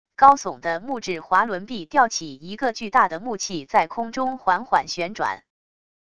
高耸的木质滑轮臂吊起一个巨大的木器在空中缓缓旋转wav音频